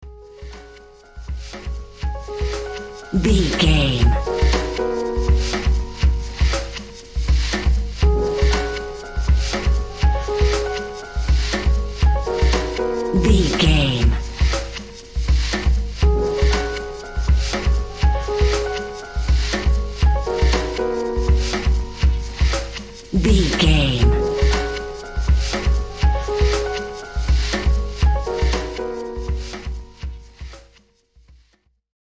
Mixolydian
D♭
electronic
techno
trance
synths
drone
glitch